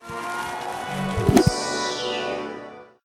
explosion1.ogg